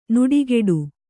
♪ nuḍigeḍu